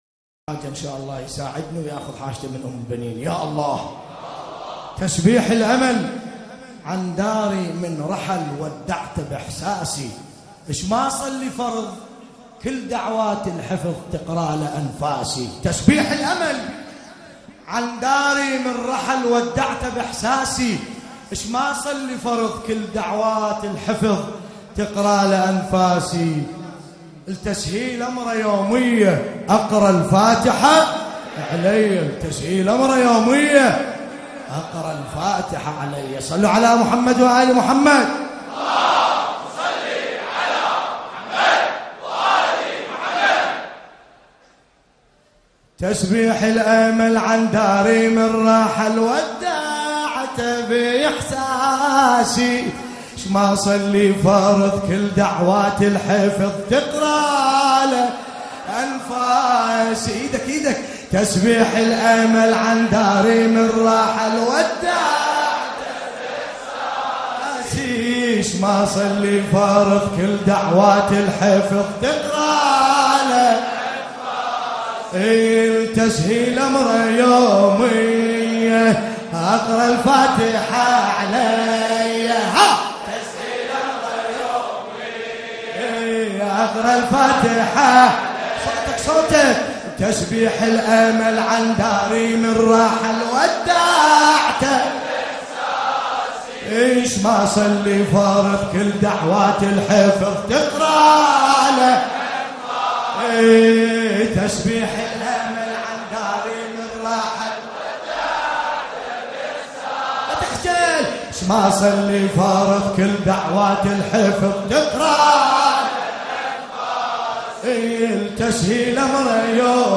ملف صوتی تسبيح الأمل بصوت باسم الكربلائي
الرادود : الحاج ملا باسم الكربلائی